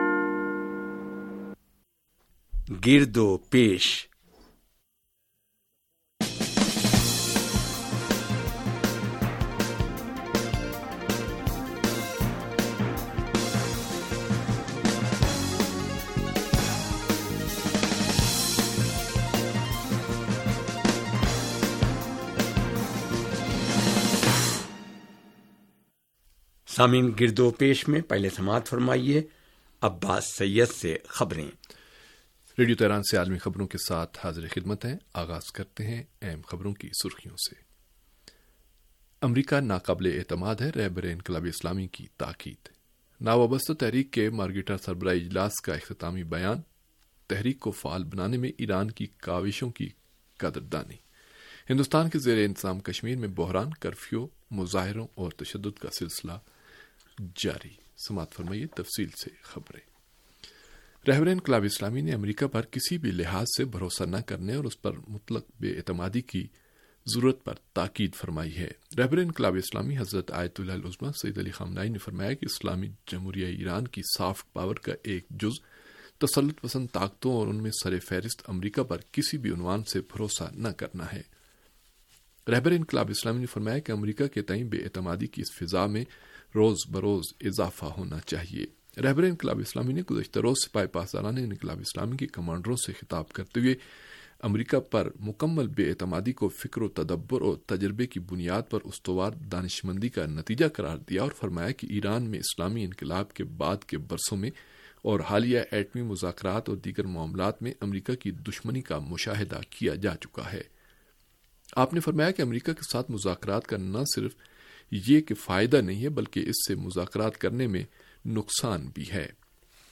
ریڈیو تہران کا سیاسی پروگرام گردوپیش و عالمی خبریں